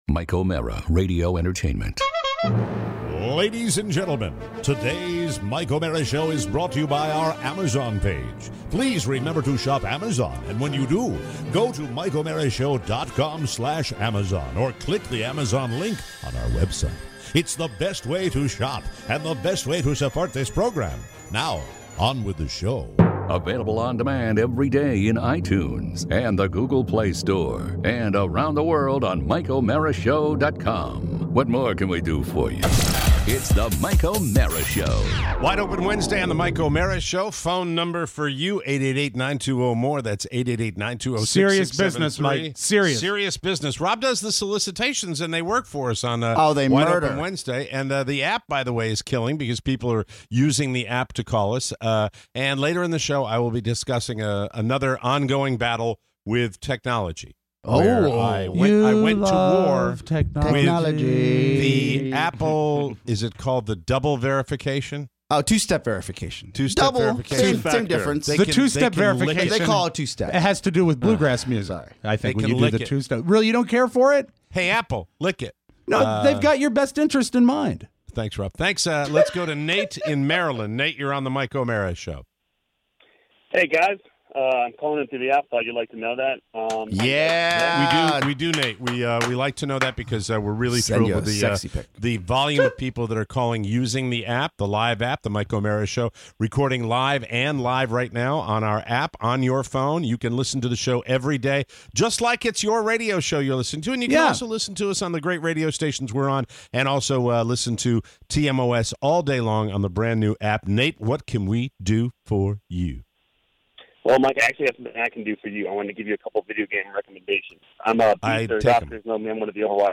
Your calls on Wide Open Wednesday!